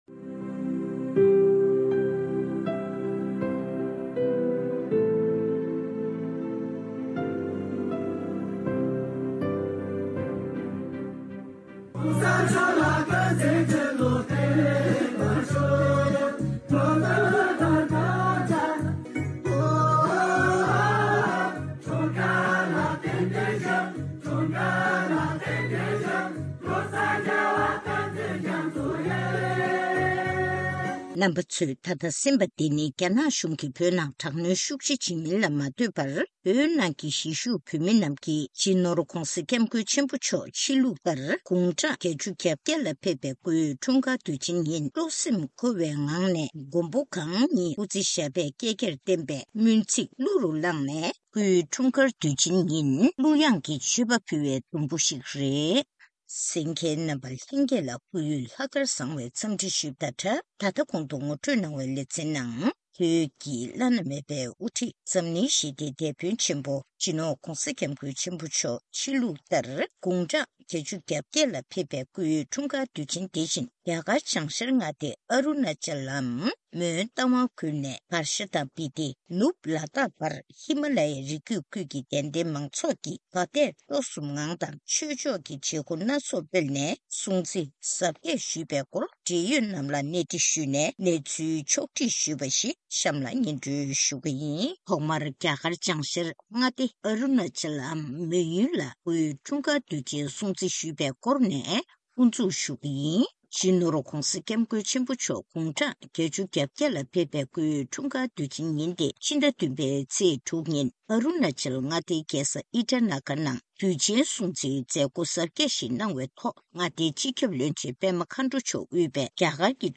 གནས་འདྲི་ཞུས་ནས་གནས་ཚུལ་ཕྱོགས་ཞུས་པ་ཞིག་གསན་གནང་གི་རེད།